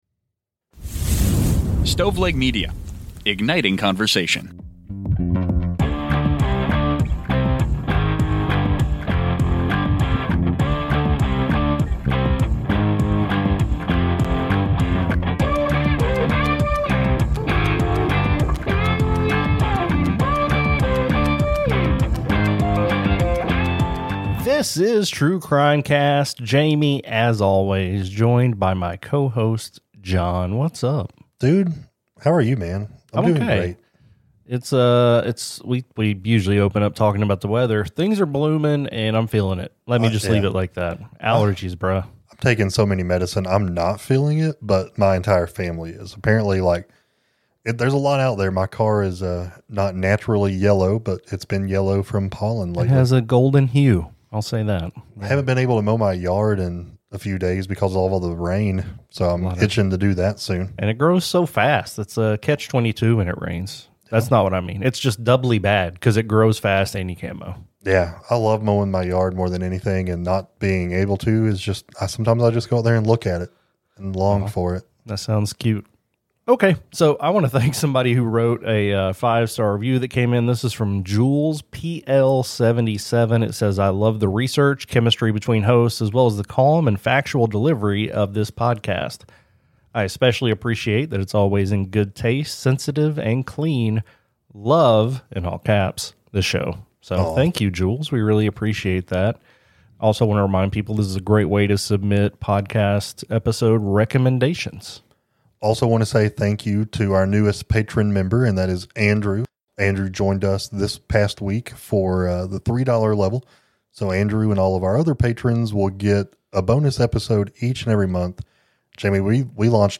Society & Culture, Personal Journals, Documentary, True Crime